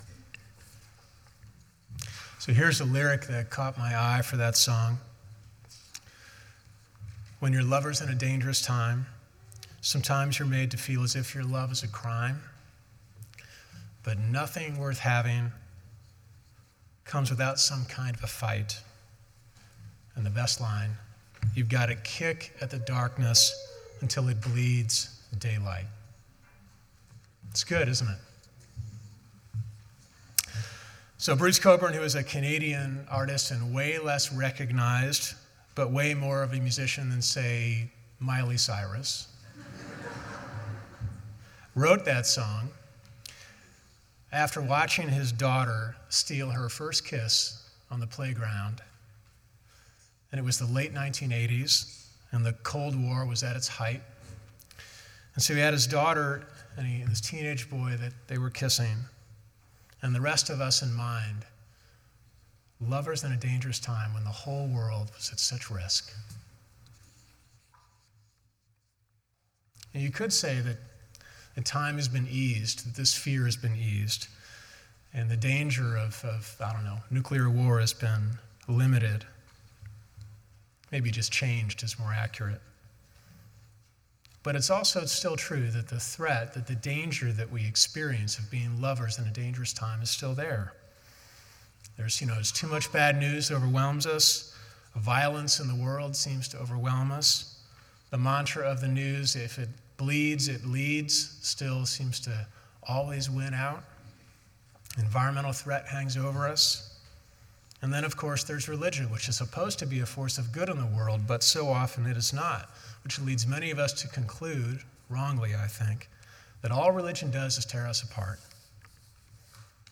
Sermon1_5_14.mp3